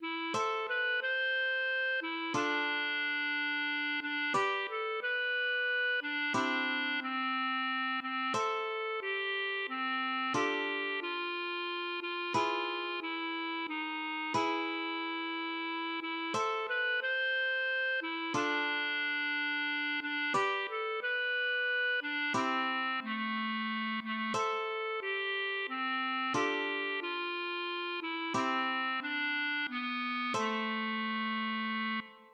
Wiki-Liedermappe